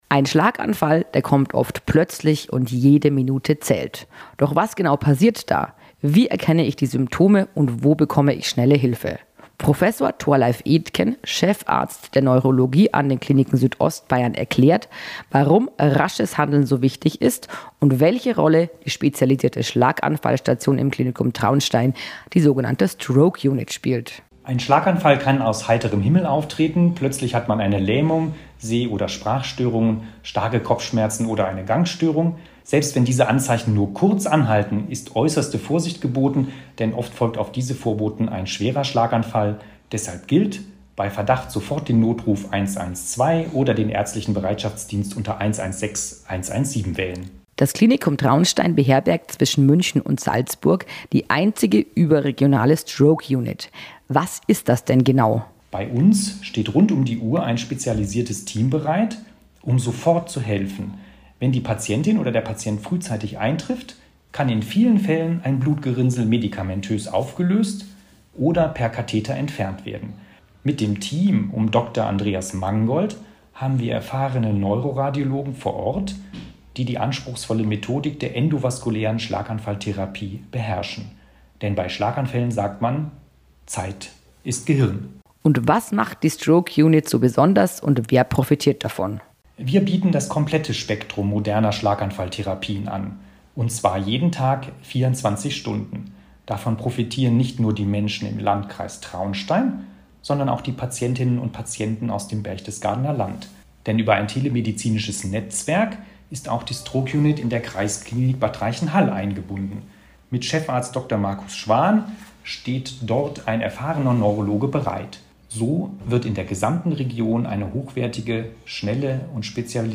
Im Format "Gesundheit KOMPAKT" mit der Bayernwelle Südost sprechen unsere Experten über medizinische Themen aus den Kliniken Südostbayern AG.